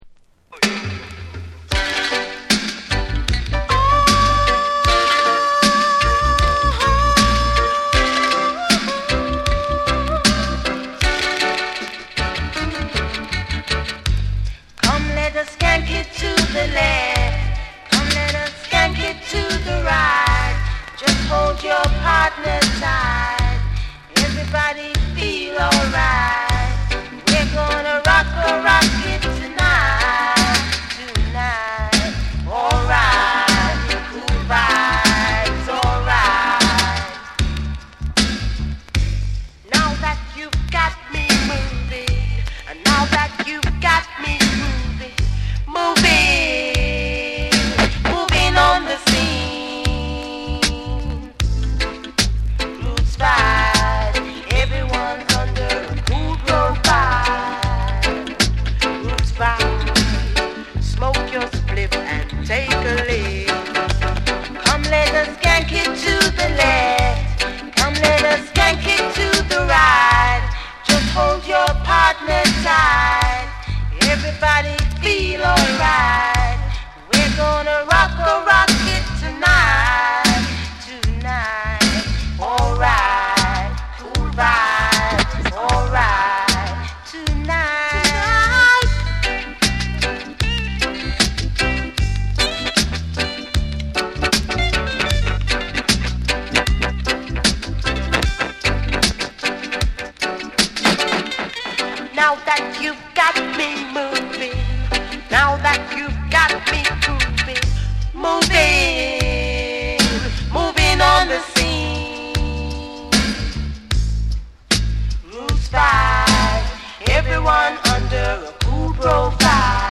ミッドテンポのルーツ・リディムに乗せて、どこか儚げでメロウなハーモニーが心に染みる
REGGAE & DUB